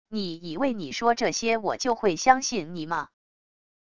你以为你说这些我就会相信你吗wav音频生成系统WAV Audio Player